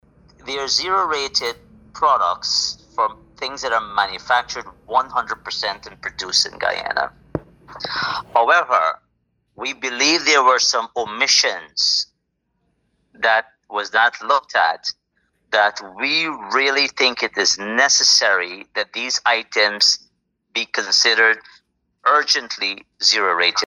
During an interview with the National Communications Network